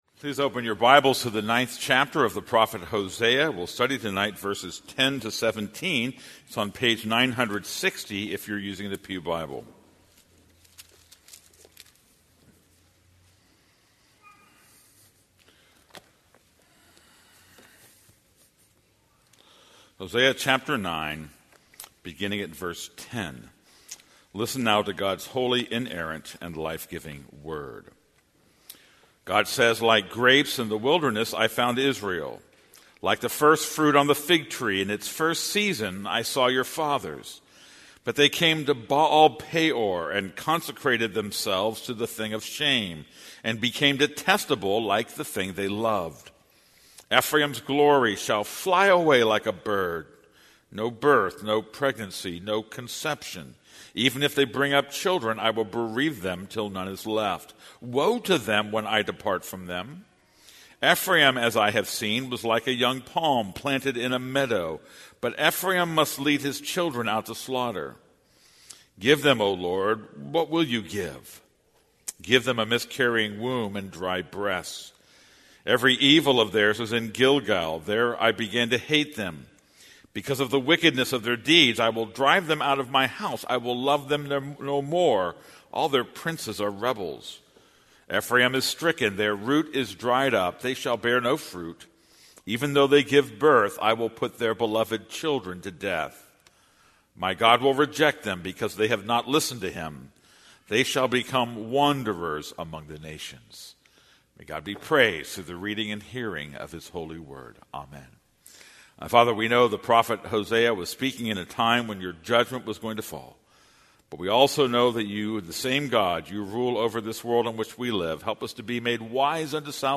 This is a sermon on Hosea 9:10-17.